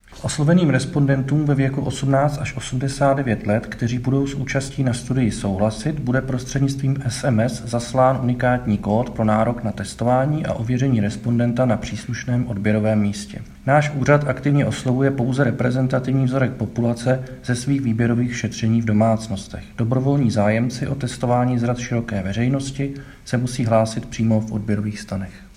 Vyjádření Marka Rojíčka, předsedy ČSÚ, soubor ve formátu MP3, 776.57 kB